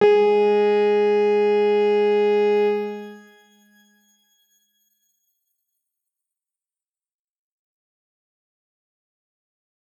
X_Grain-G#3-pp.wav